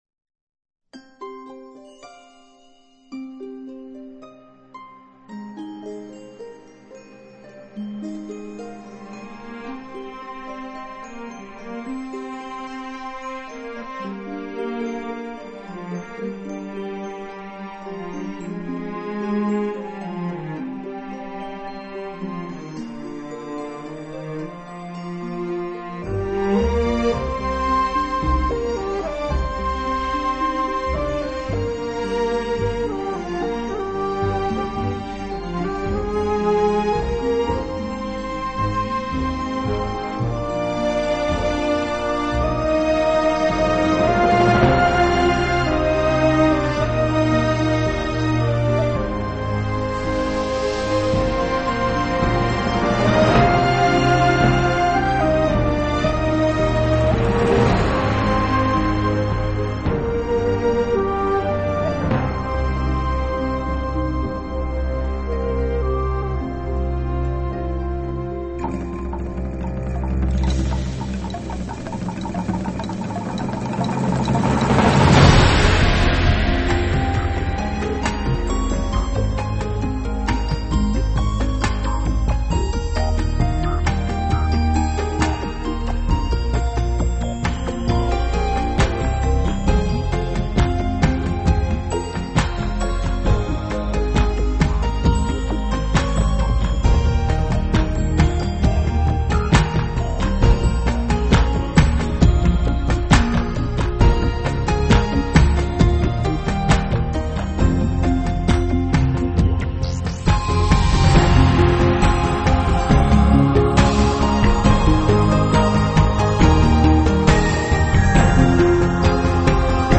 大开大阖、气宇非凡。